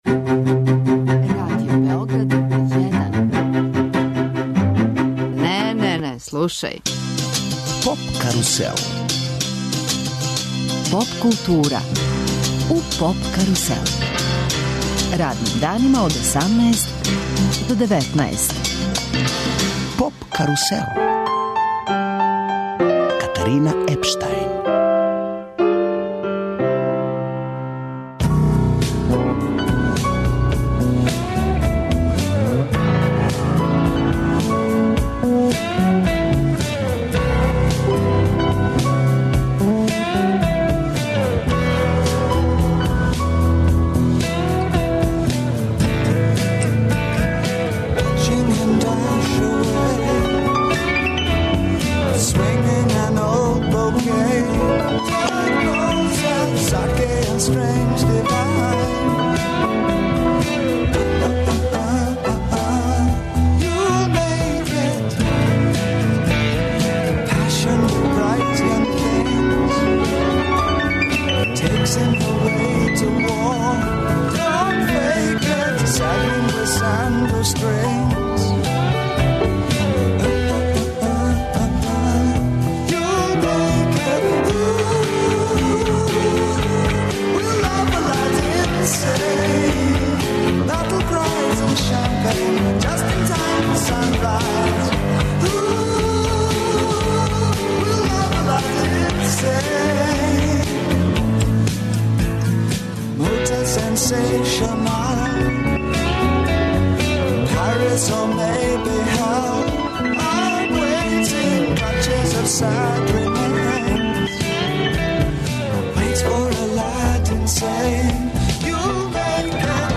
Гости су нам чланови бенда Дингоспо Дали, победници првог Бунт рок мастерс телевизијског фестивала.